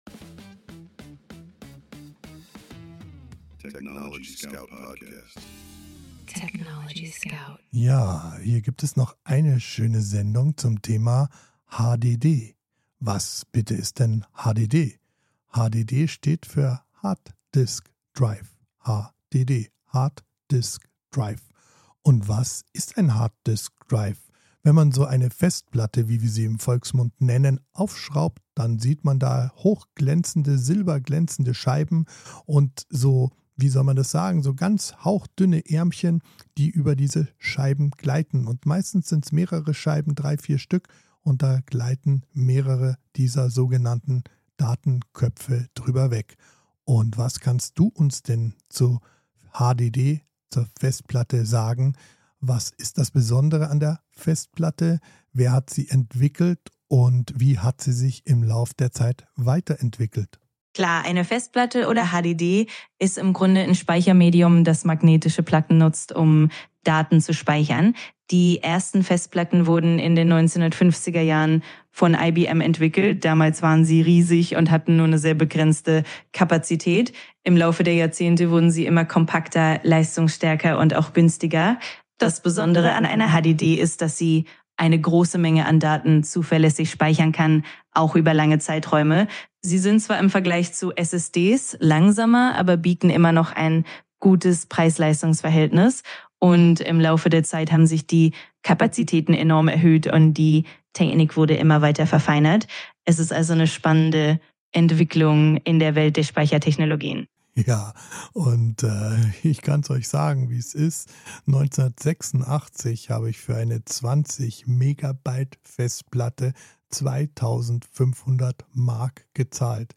gemeinsam mit seiner digitalen Co-Moderatorin ChatGPT jeden
Mensch und KI sprechen miteinander – nicht gegeneinander.